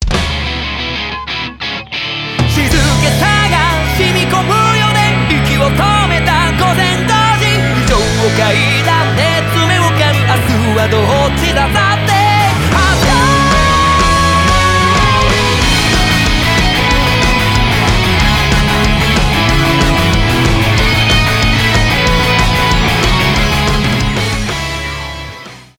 j-rock